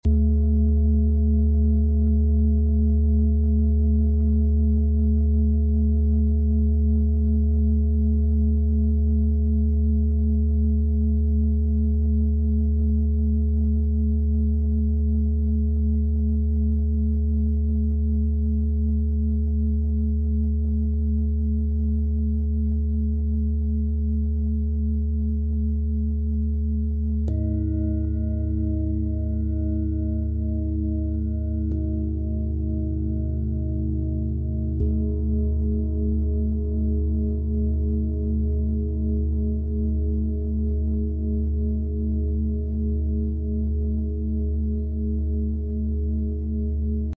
75 Hz Sound Healing Meditation Sound Effects Free Download
75 hz sound healing meditation Stress Reduction: The low frequency of 75 Hz promotes deep relaxation, helping to reduce stress and anxiety by calming the nervous system. Enhanced Meditation: The soothing vibrations assist in deepening meditation practices, allowing for a more centered and focused mind.